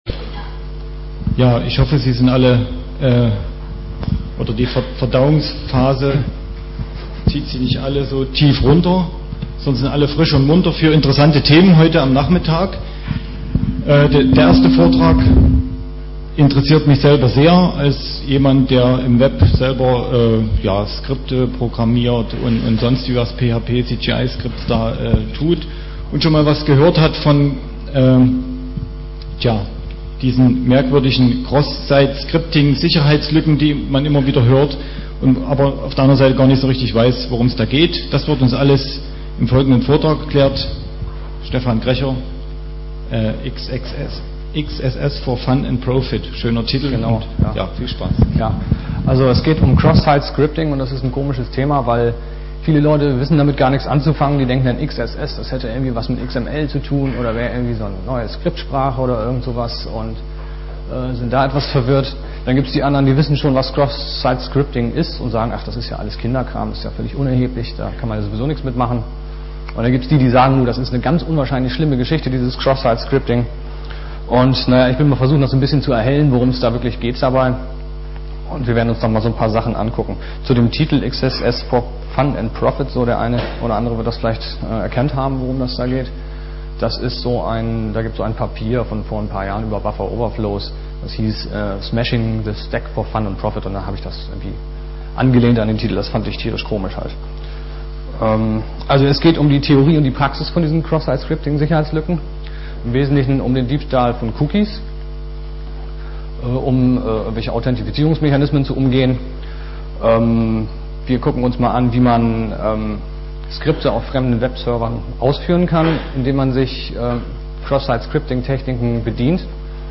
5. Chemnitzer Linux-Tag
Samstag, 14:00 Uhr im Raum V2 - WWW-Scripting